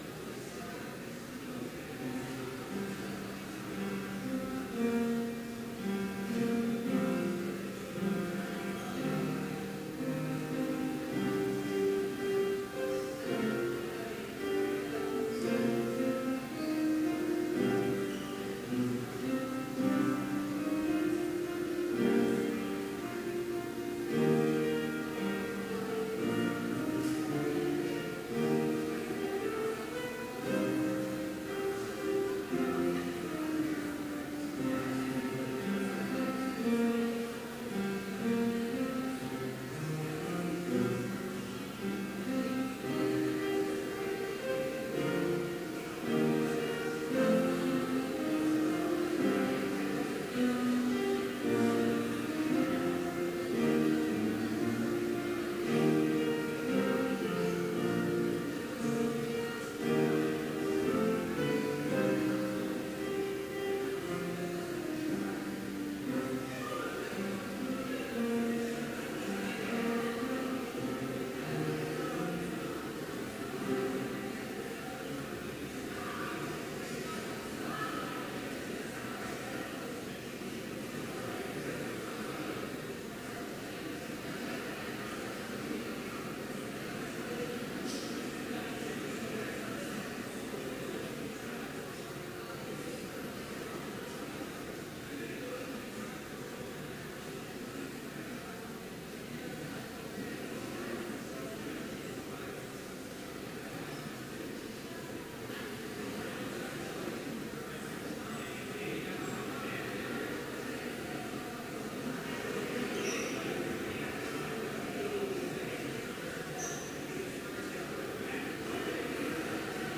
Complete service audio for Chapel - October 17, 2016
Prelude
Hymn 517, vv. 1 – 3 & 5, If God Himself Be For Me